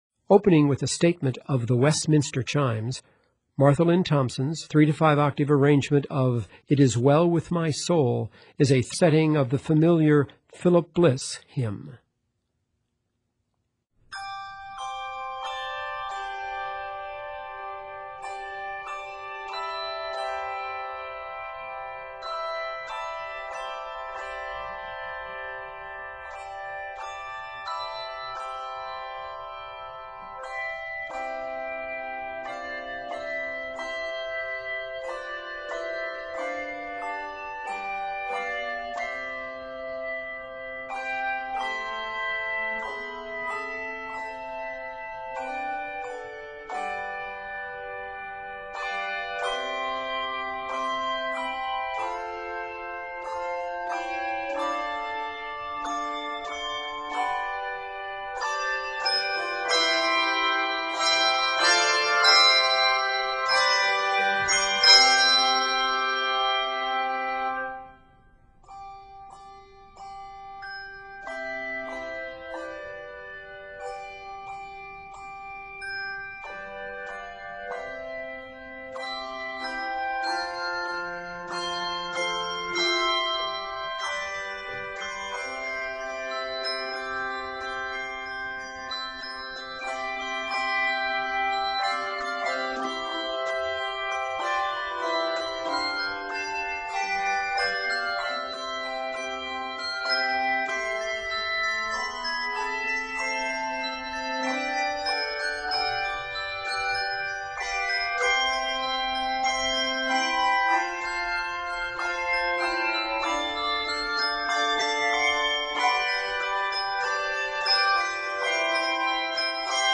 Opening with a statement of the Westminster Chimes